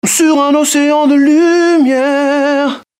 PLAY Money SoundFX